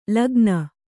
♪ lagna